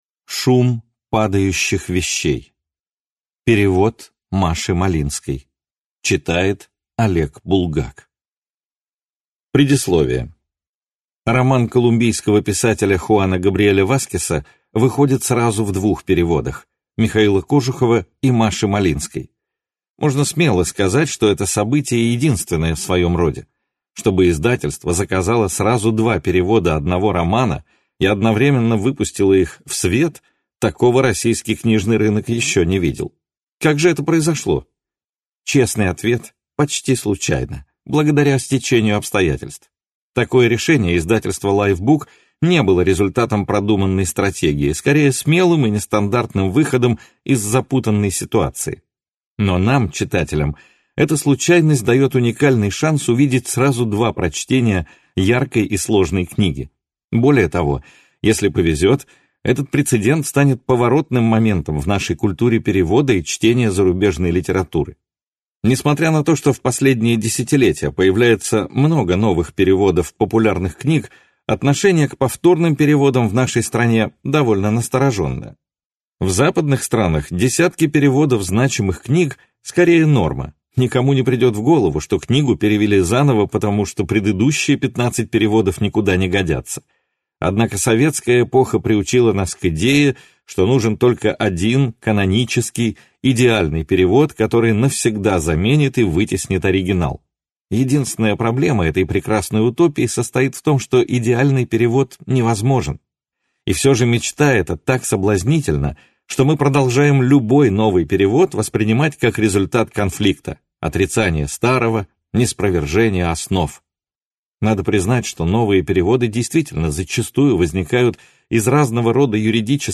Аудиокнига Шум падающих вещей | Библиотека аудиокниг